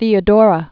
(thēə-dôrə) 508?-548.